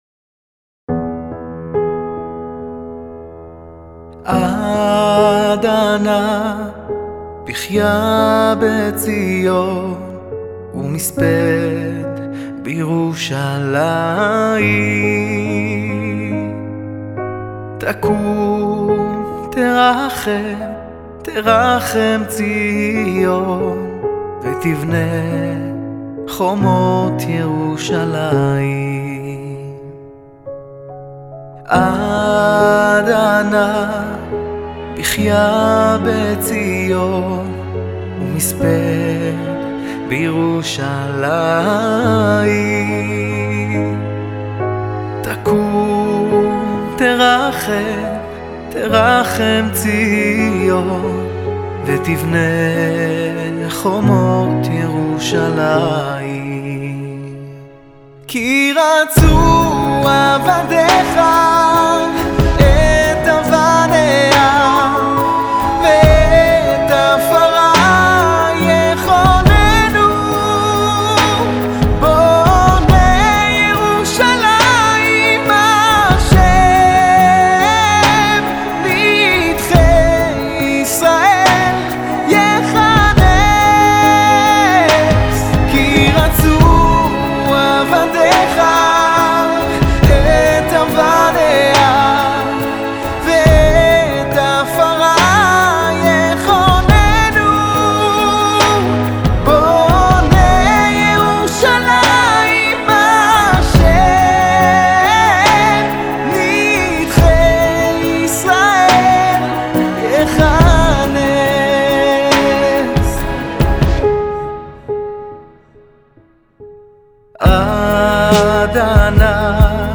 שיר רגש חסידי
שירתו המרגשת